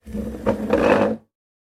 На этой странице собраны разнообразные звуки дивана: от скрипов старых пружин до мягкого шуршания обивки.
Звук передвижения дивана по деревянному полу